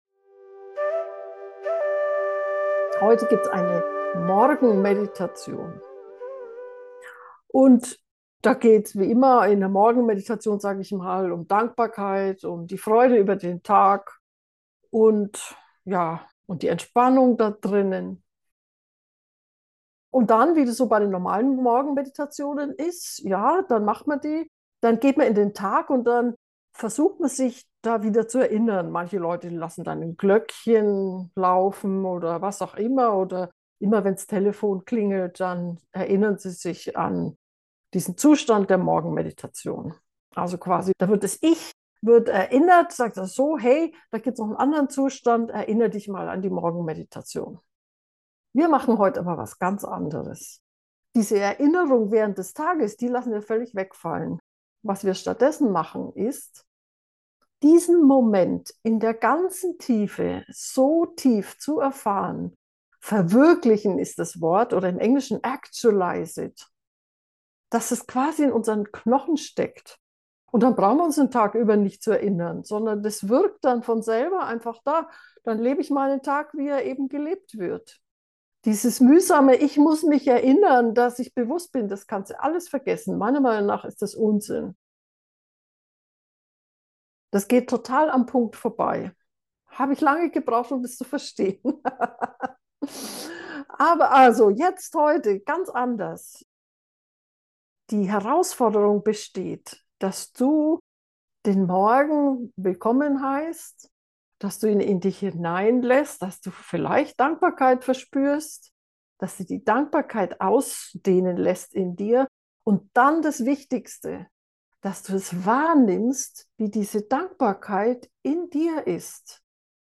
In jeder meiner bisherigen, geführten Morgenmeditationen geht es um einen guten Zustand, morgens, gleich beim Aufwachen: um Hingabe, um Göttlichkeit, um Freude. Auch in dieser Morgenmeditation beginnen wir mit der Freude über unsere Einzigartigkeit.
morgenmeditation-mit-geheimnis.mp3